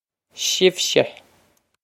Sibhse shiv-shah
Pronunciation for how to say
shiv-shah
This is an approximate phonetic pronunciation of the phrase.